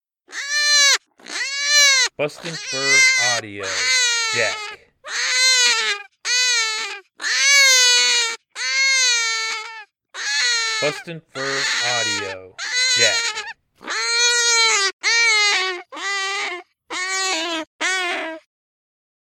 Young Jack Rabbit in distress that calls all predators.